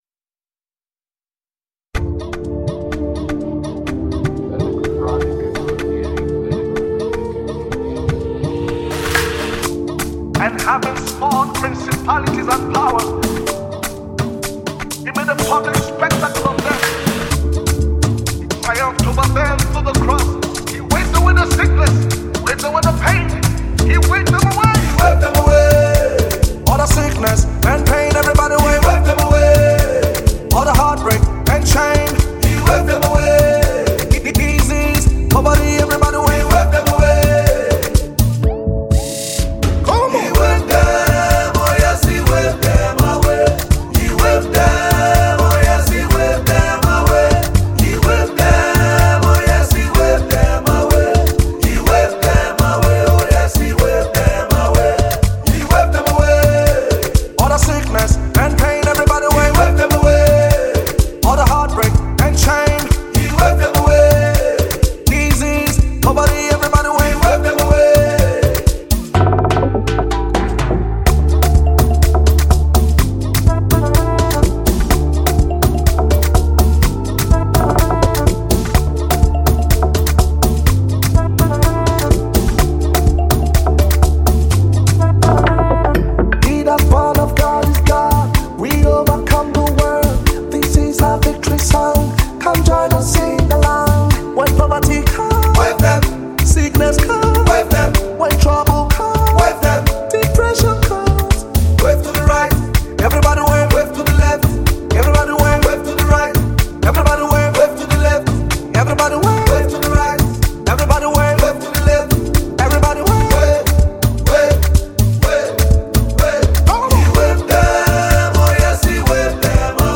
Category: Gospel Music Genre: Afrobeats